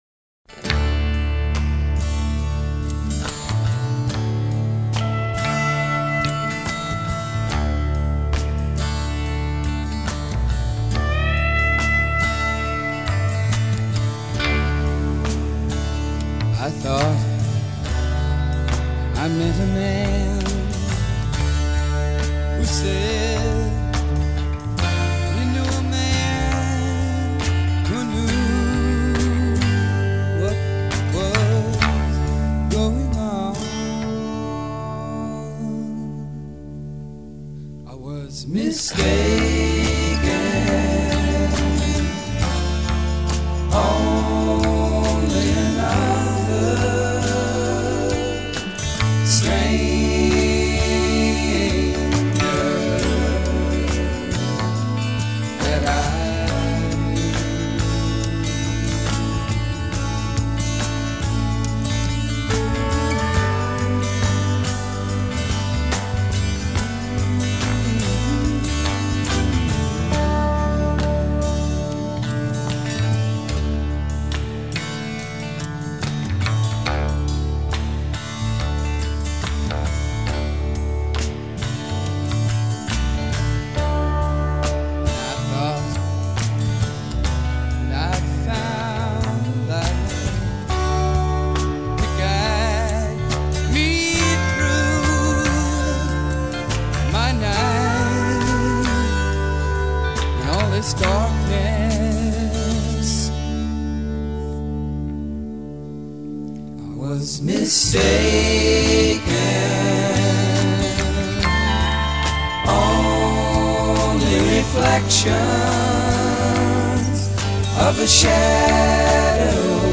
meandering and hippy